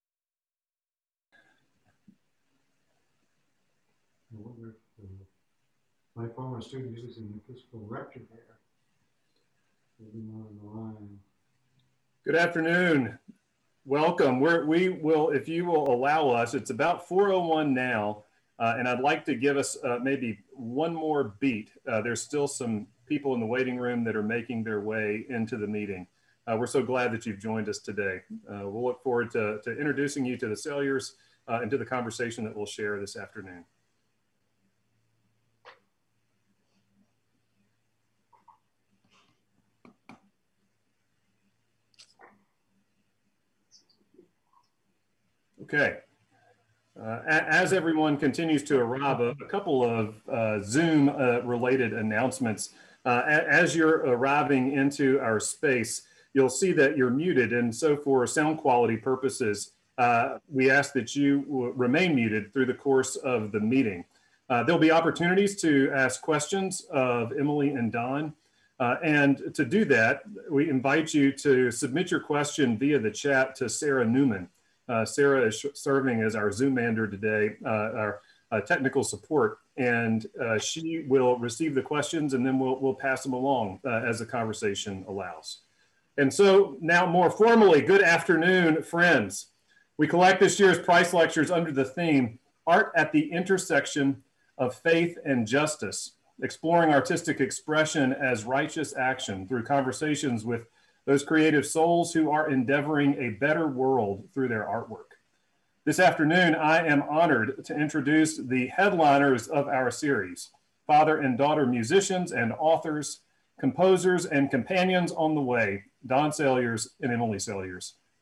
(captured from the zoom livestream)